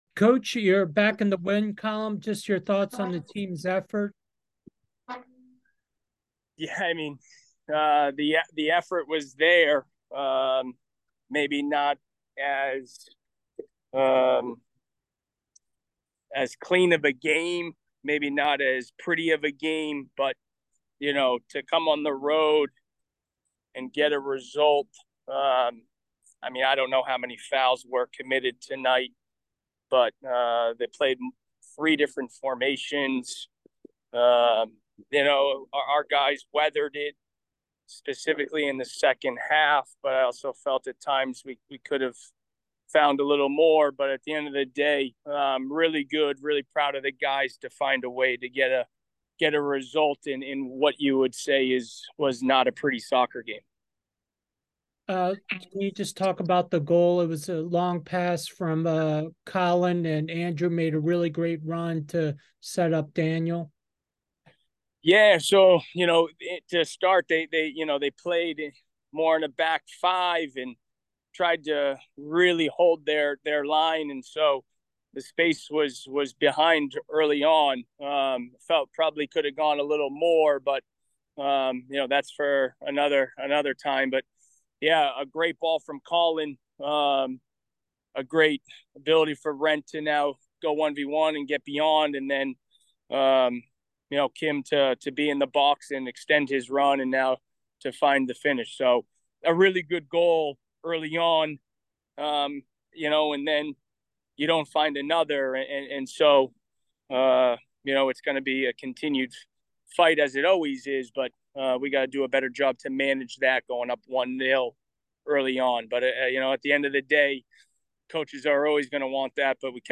Saint Joseph's Postgame Interview